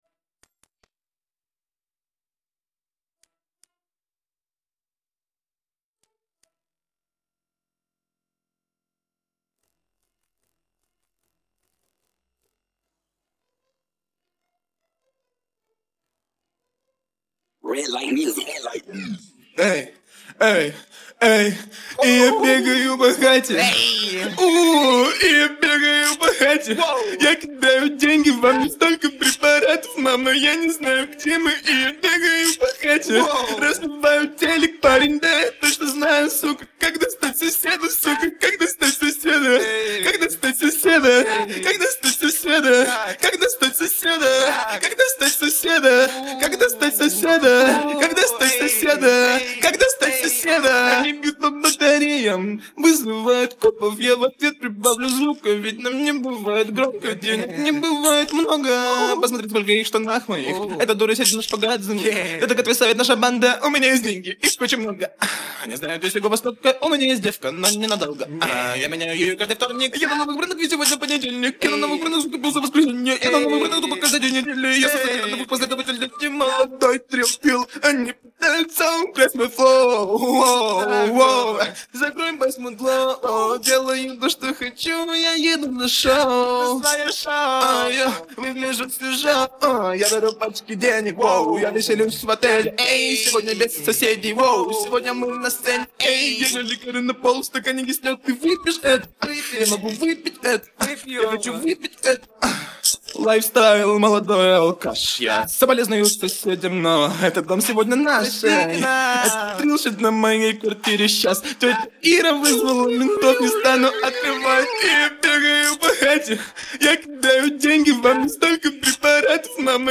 Lauluosuus